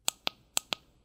На этой странице вы найдете подборку звуков, связанных с работой фонариков: щелчки кнопок, гудение светодиодов, шум переключателей.
Звук включення та вимкнення ліхтарика